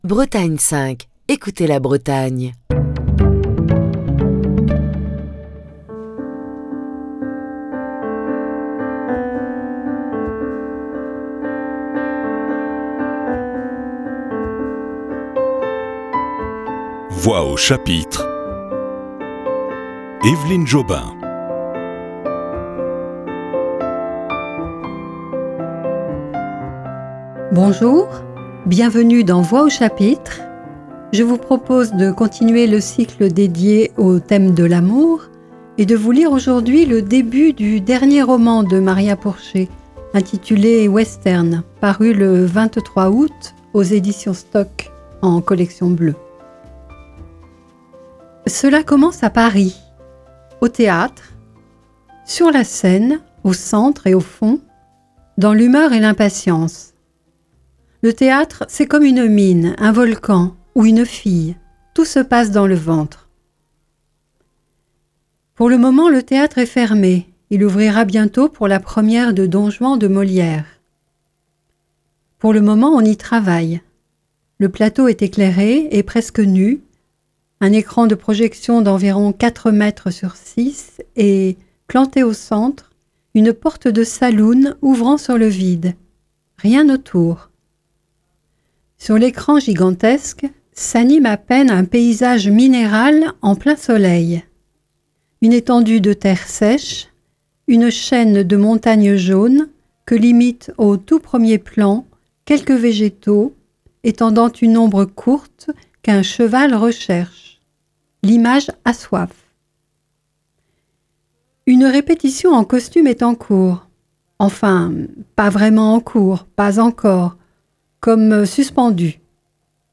la lecture du début du nouveau roman de Maria Pourchet, Western, paru chez Stock en aout 2023.